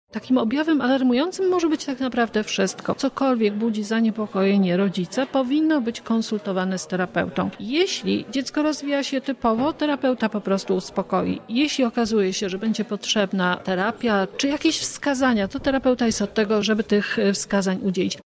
Konferencja logopedyczna